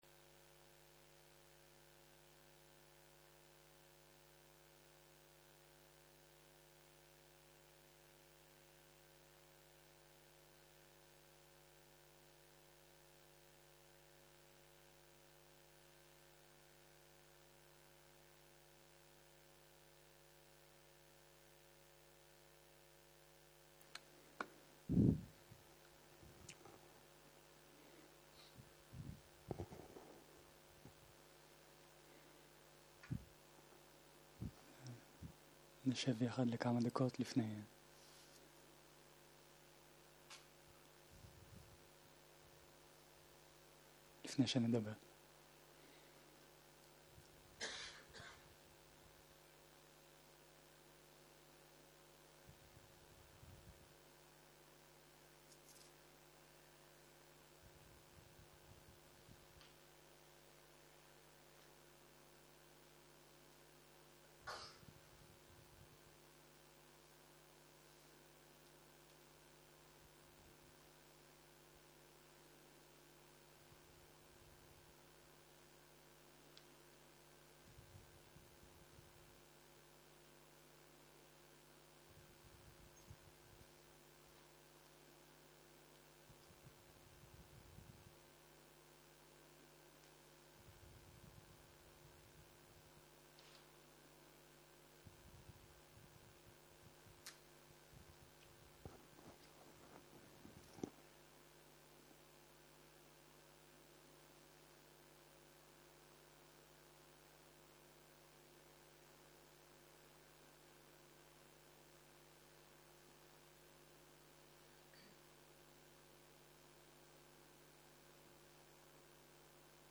Dharma type: Dharma Talks שפת ההקלטה